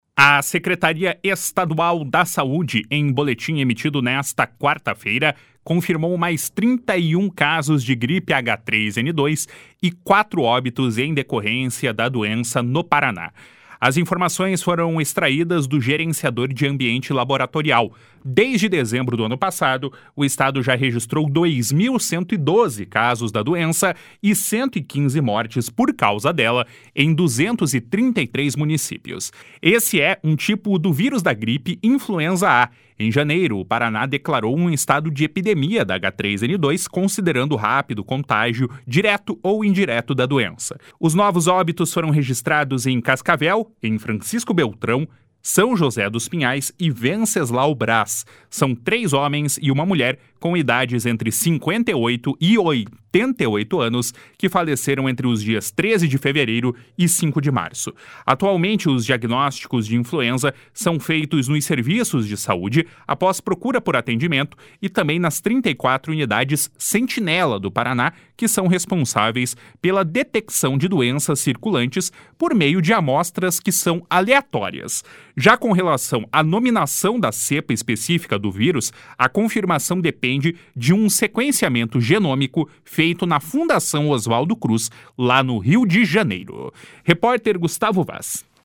BOLETIM QUINZENAL DA H3N2.mp3